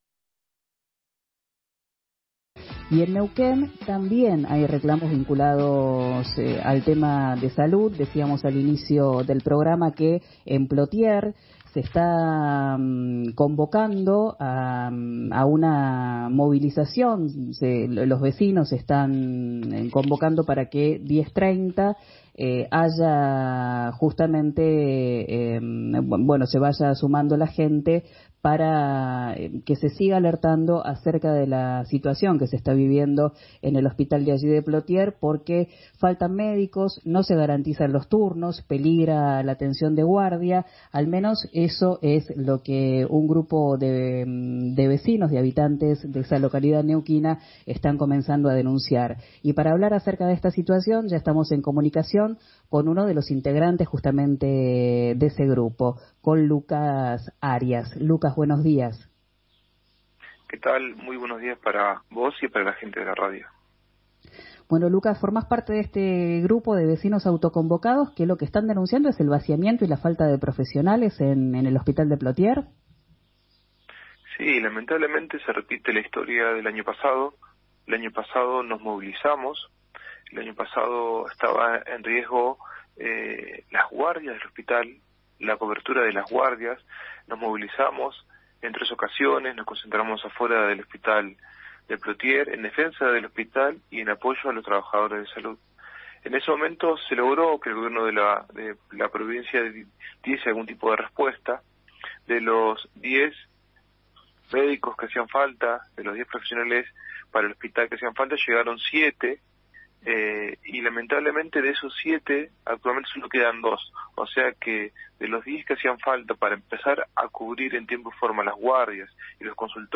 declaraciones en “Arranquemos” de RÍO NEGRO RADIO